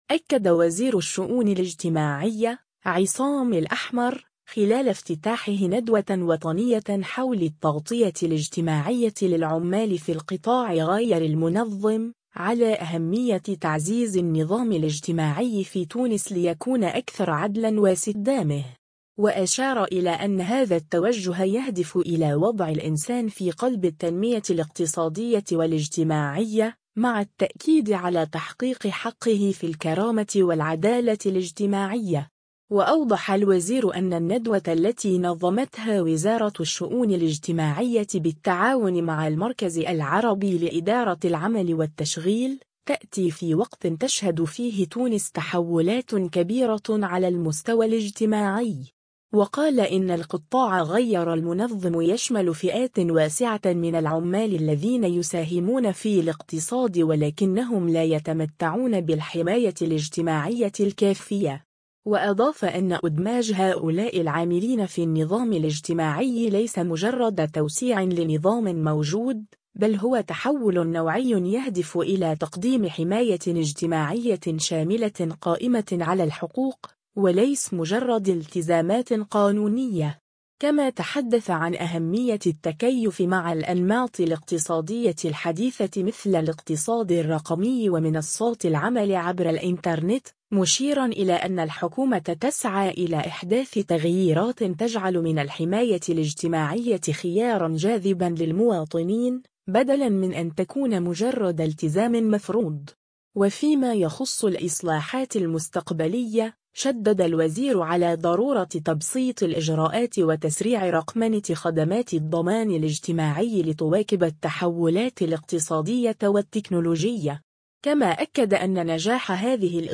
أكد وزير الشؤون الاجتماعية، عصام الأحمر، خلال افتتاحه ندوة وطنية حول “التغطية الاجتماعية للعمال في القطاع غير المنظم”، على أهمية تعزيز النظام الاجتماعي في تونس ليكون أكثر عدلاً واستدامة.